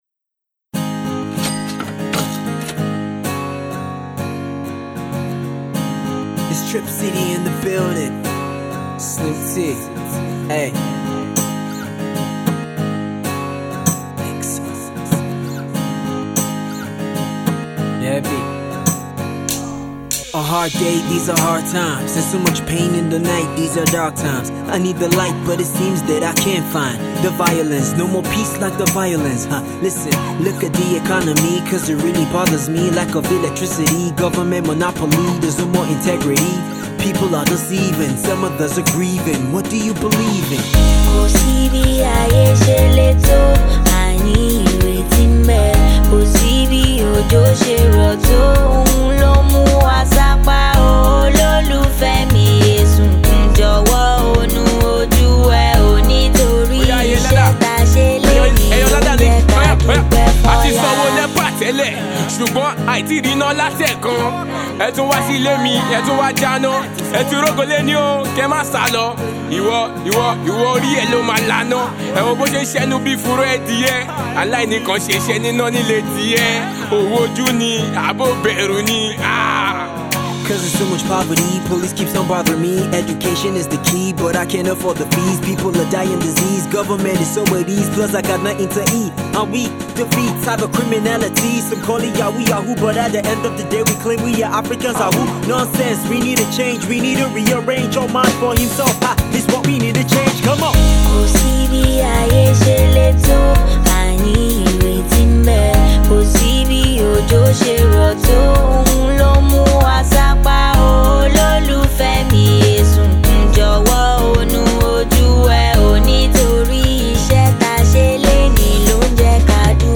a more toned down ethnic Hip-Hop track
enchanting vocal musings